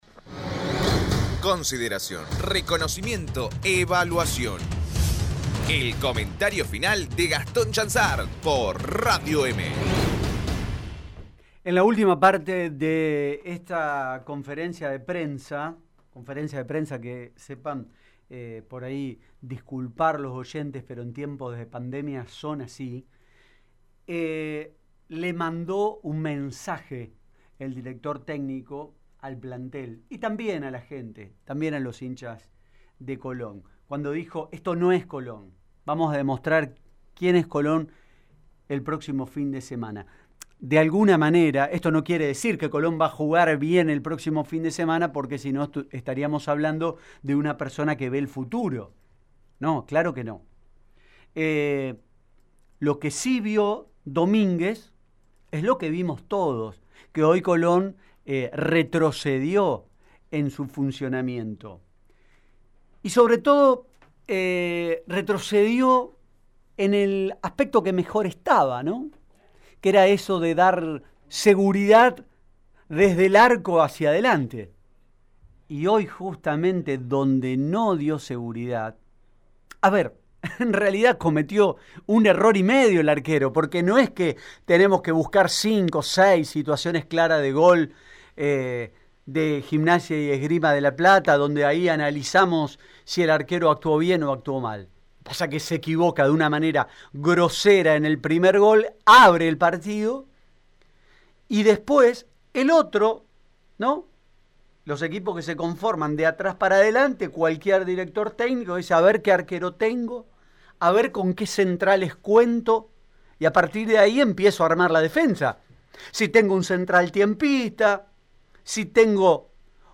comentario final